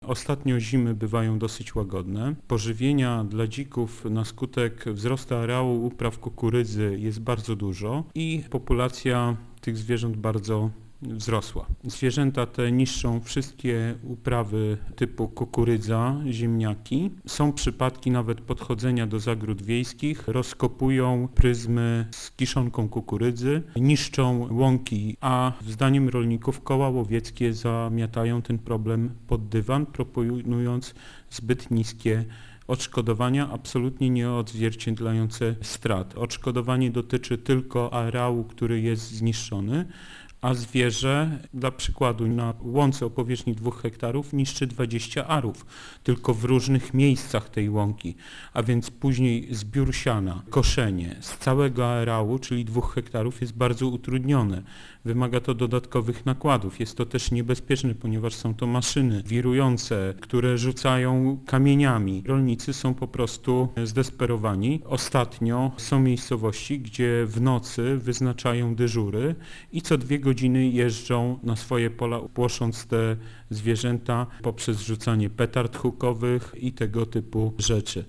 Wójt gminy Kłoczew Zenon Stefanowski uważa, że poszkodowanych może być nawet około stu rolników.